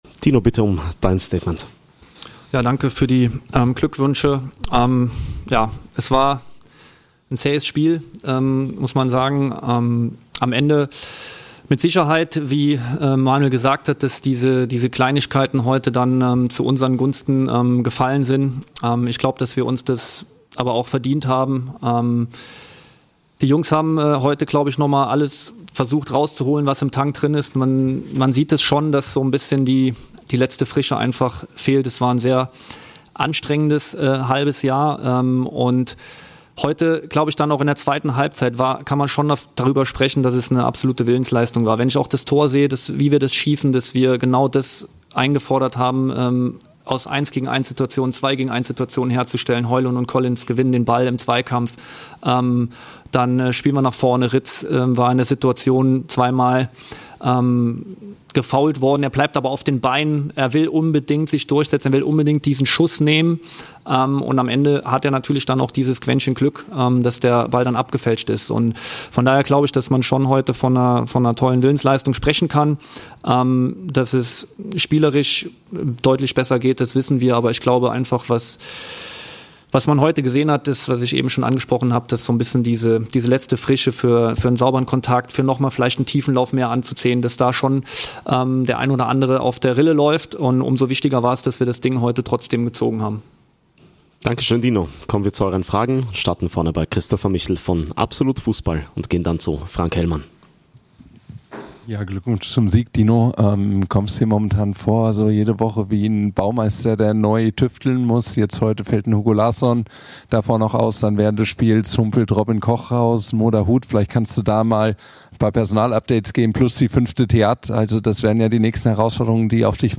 Die Cheftrainer Dino Toppmöller und Manuel Baum stellen sich den
Fragen der Journalisten auf der Pressekonferenz nach unserem